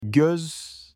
تلفظ Göz هم بسیار ساده است: چیزی بین “گوز” و “گُز”. حرف “ö” در ترکی استانبولی شبیه “اُ” در کلمه “پُل” در فارسی تلفظ می‌شود، اما با گرد کردن بیشتر لب‌ها.